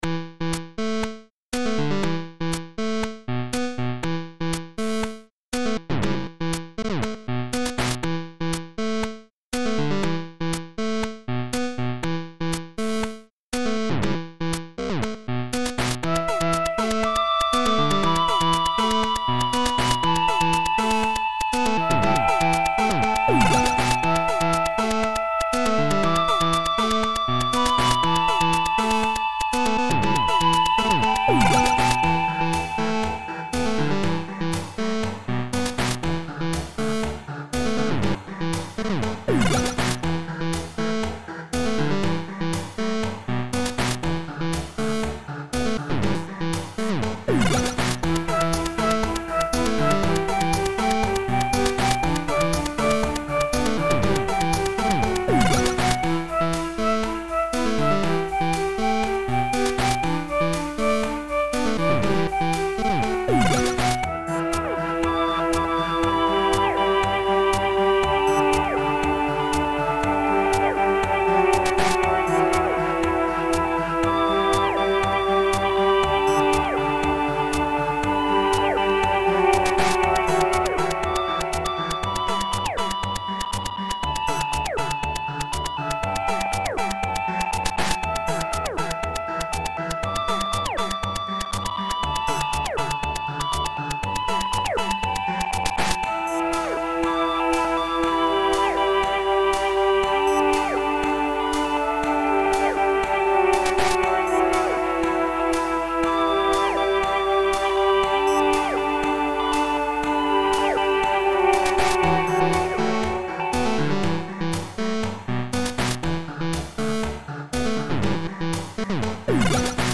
I feel like this one was a little more repatative than I would have liked, but it was fun to make.
I love the toad vst which is what I think you used for the nes sounds? a very nice "get work done" song.
i really like how you put the tape stop on the flutey sounding thing. if that makes any sence.